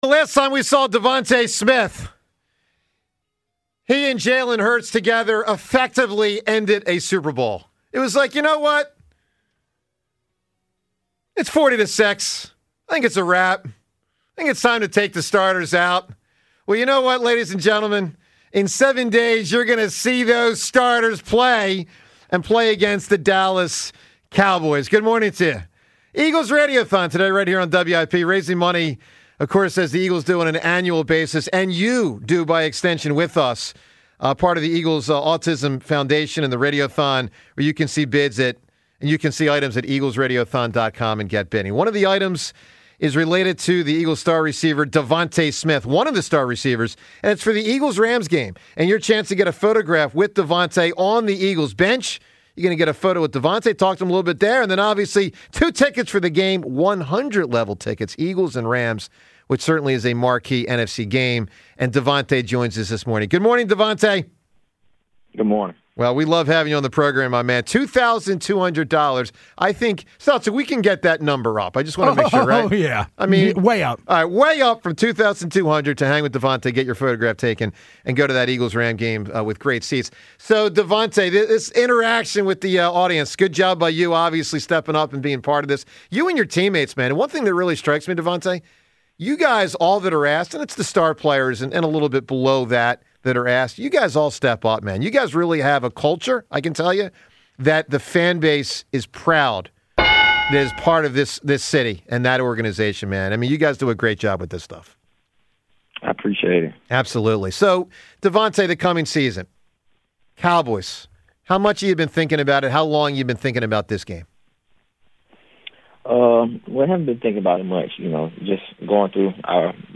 New episodes drop Monday-Friday. You can catch the 94WIP Morning Show live on SportsRadio 94WIP weekdays from 6–10 a.m. ET.